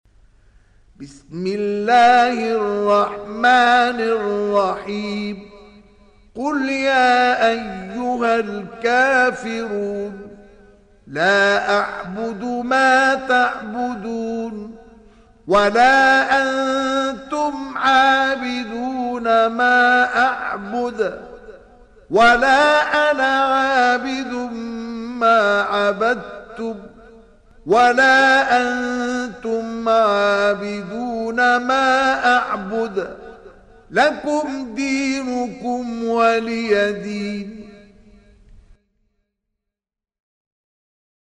Sourate Al Kafirun mp3 Télécharger Mustafa Ismail (Riwayat Hafs)